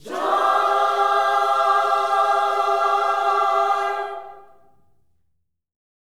JOY CMAJ 3.wav